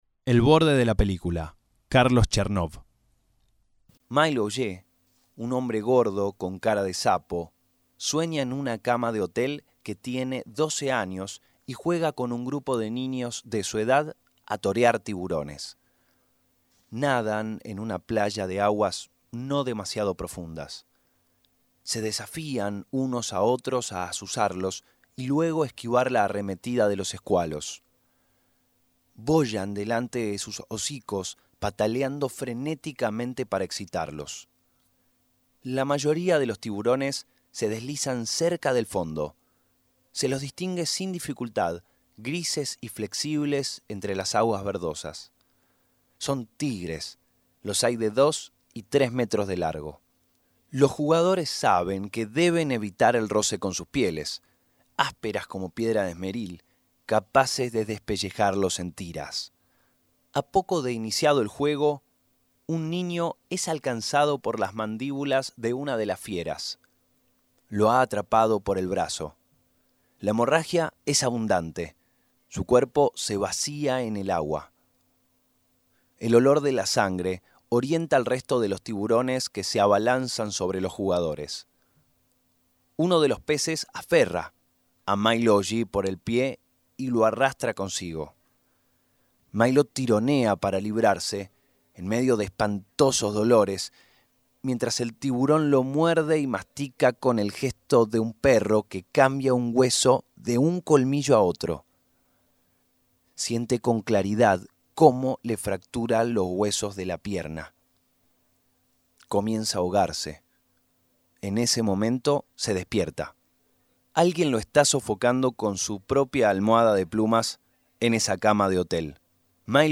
Audiocuento